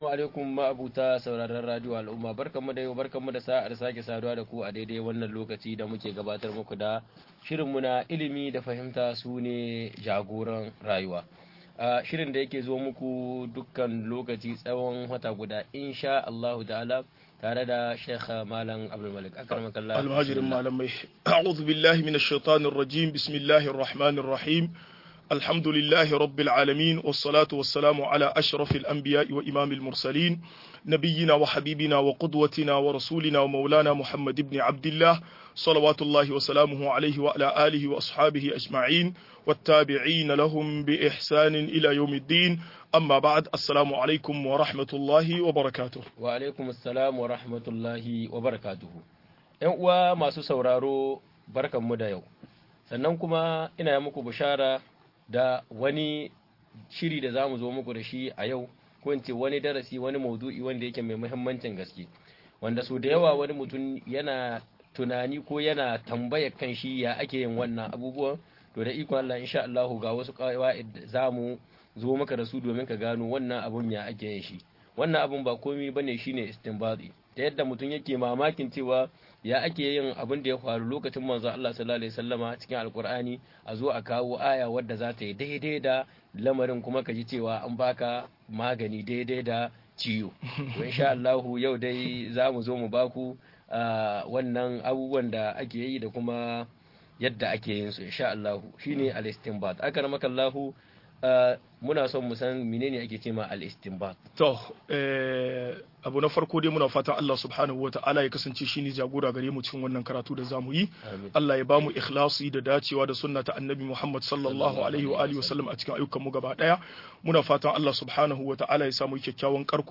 Yadda ake istimbadi - MUHADARA